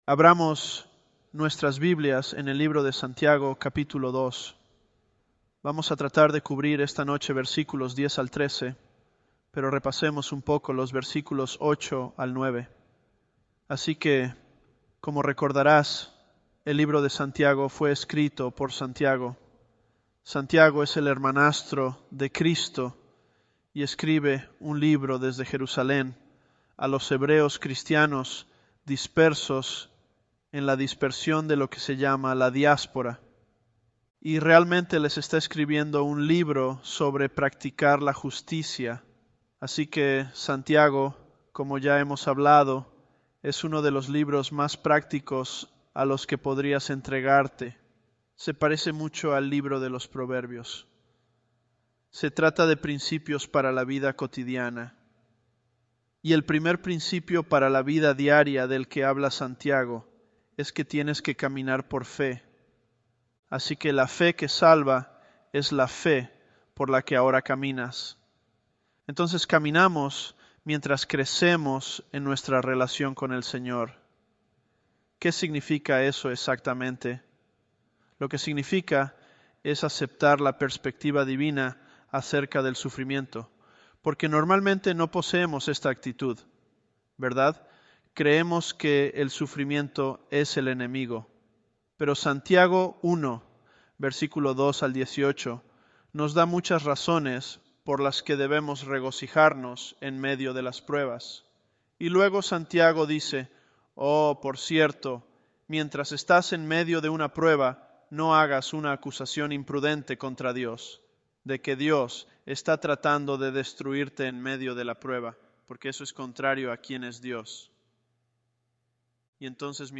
Sermons
ElevenLabs_James010.mp3